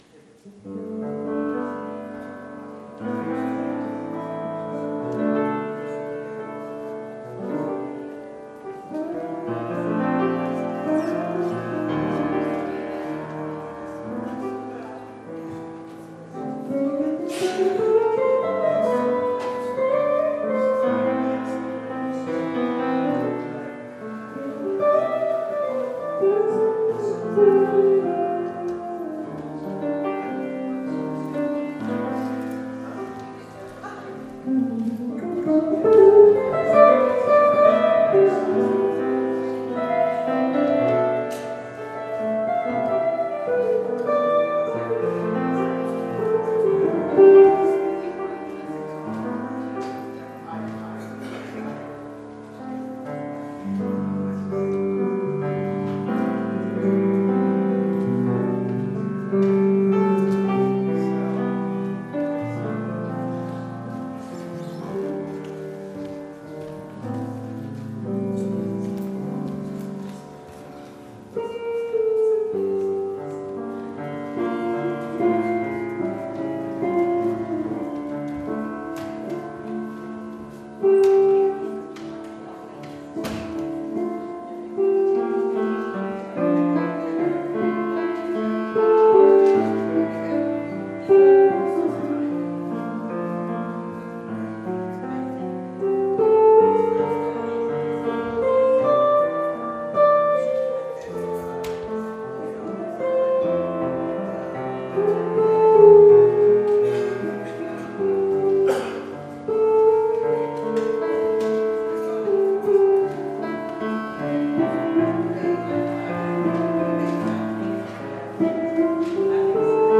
Slow Down, Go Fast - Prairie Street Mennonite Church